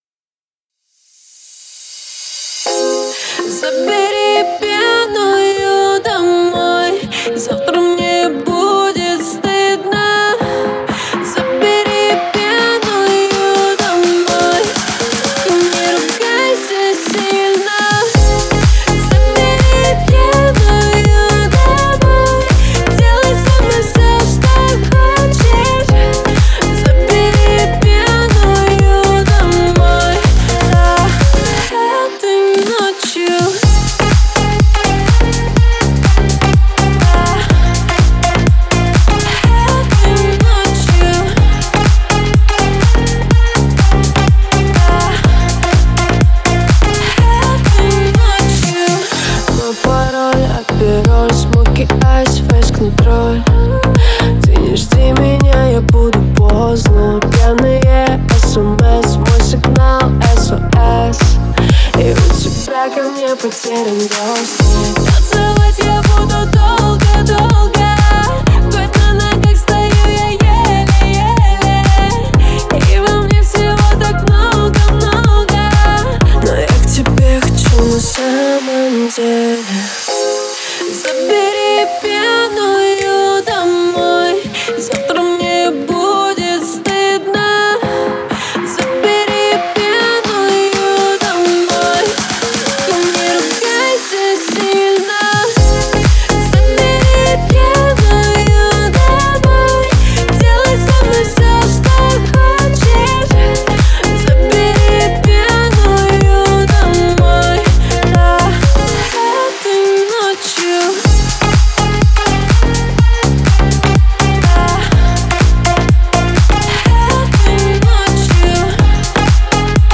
Трек размещён в разделе Русские песни / Танцевальная / 2022.